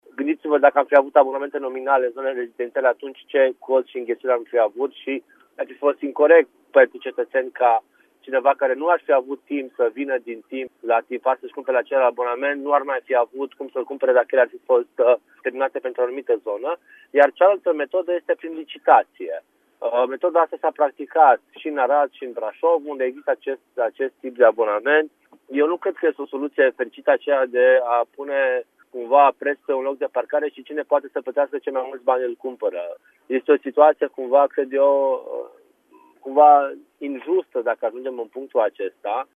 Precizarea a fost făcută la Radio Timișoara după ce mai multe persoane s-au plâns de acest tip de abonament.